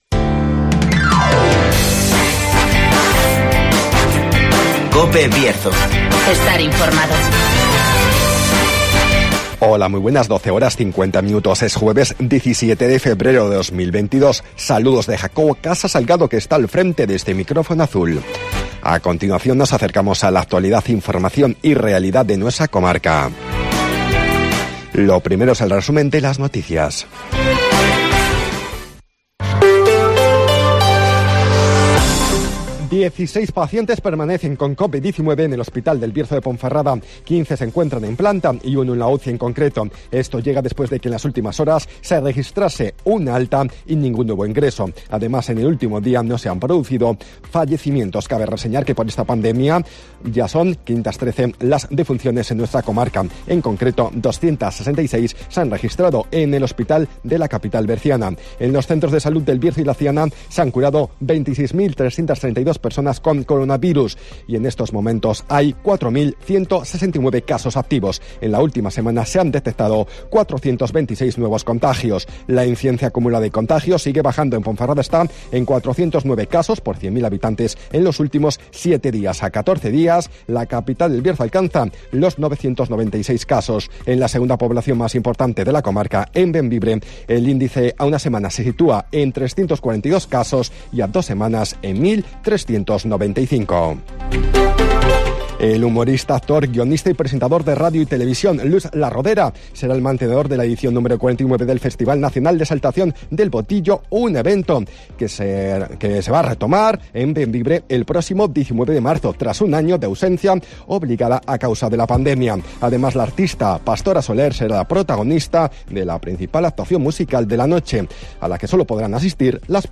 Resumen de las noticias, El Tiempo y Agenda